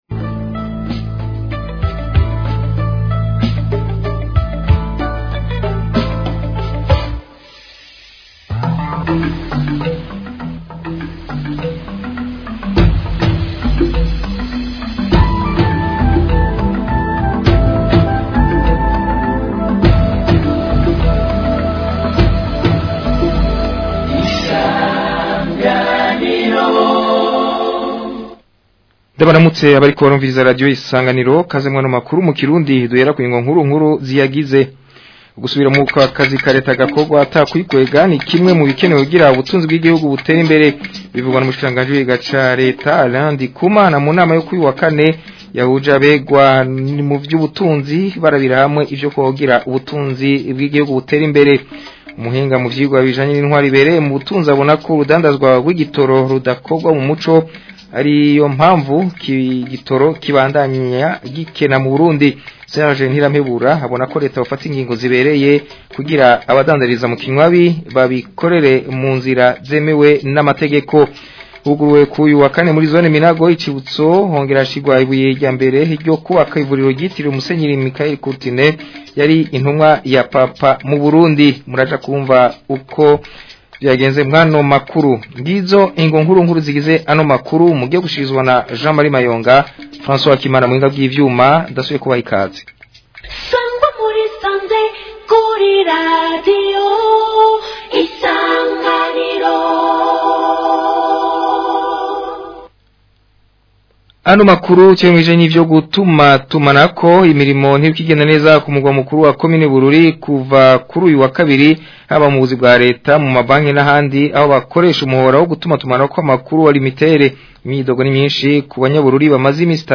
Amakuru yo ku wa 14 Myandagaro 2025